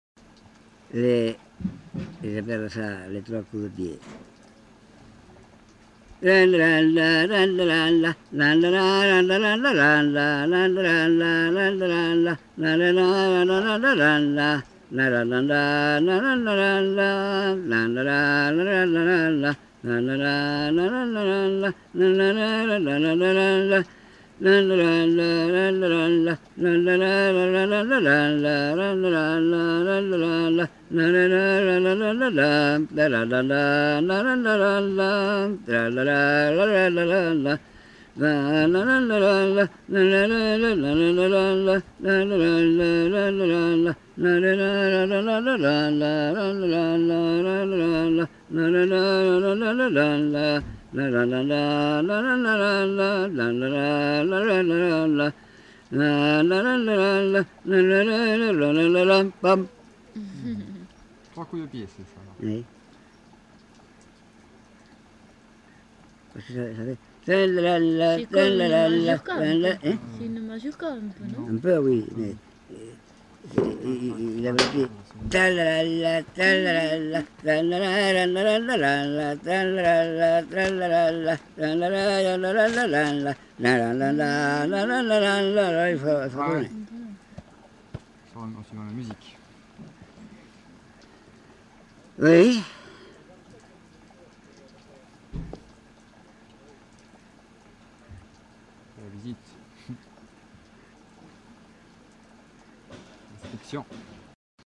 Aire culturelle : Couserans
Genre : chant
Type de voix : voix d'homme
Production du son : fredonné
Danse : tres pases